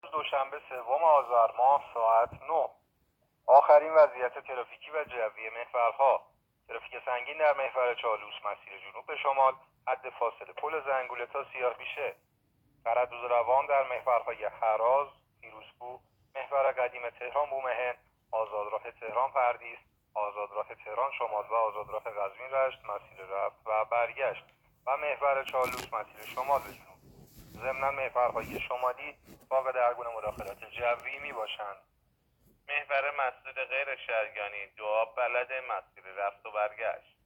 گزارش رادیو اینترنتی از آخرین وضعیت ترافیکی جاده‌ها ساعت ۹ سوم آذر؛